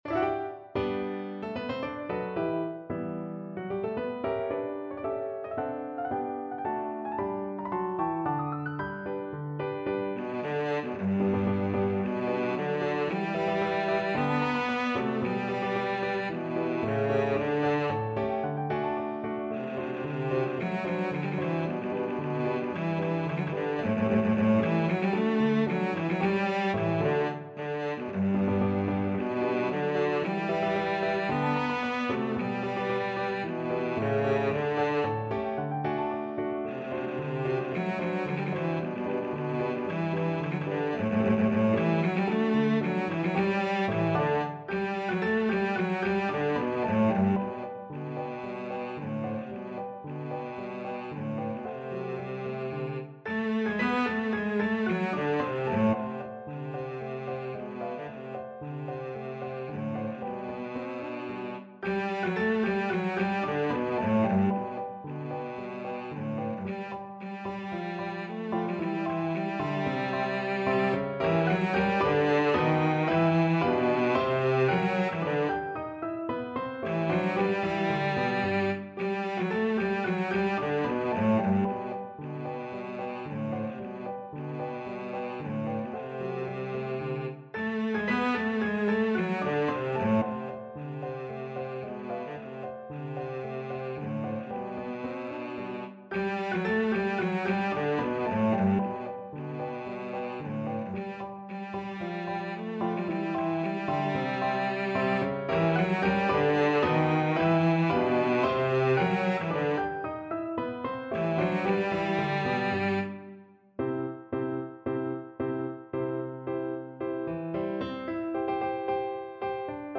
Cello
G major (Sounding Pitch) (View more G major Music for Cello )
2/2 (View more 2/2 Music)
March =c.112
Classical (View more Classical Cello Music)